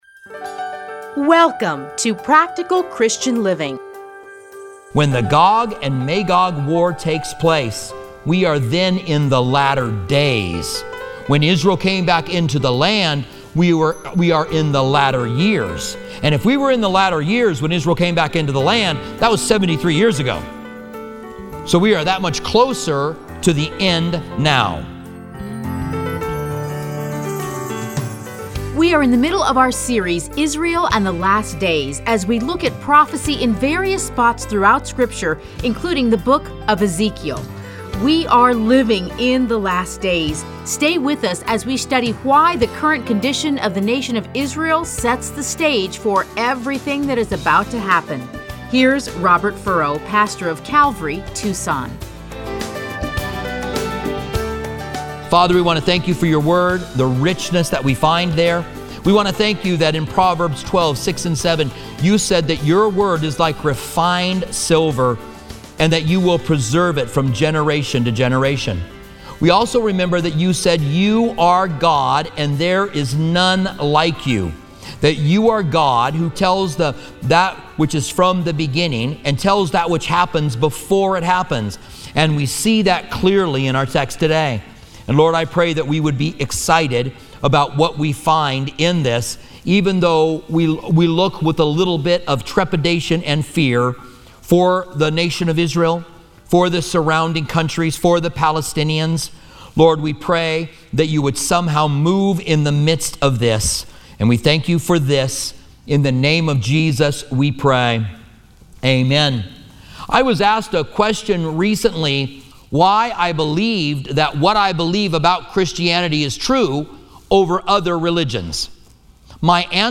Listen to a teaching from Ezekiel 36-39.